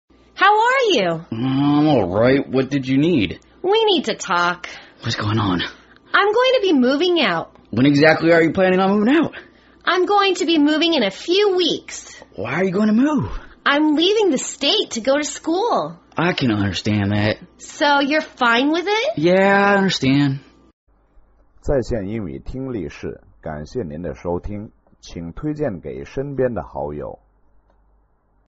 地道英语对话：Moving Out(2) 听力文件下载—在线英语听力室